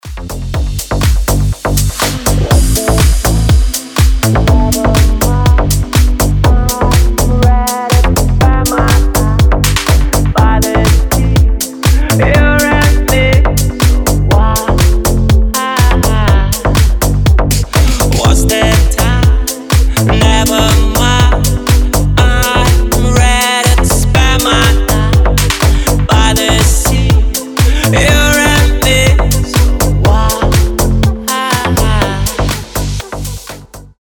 • Качество: 320, Stereo
мужской вокал
deep house
nu disco
Indie Dance
Стиль: indie dance, nu disco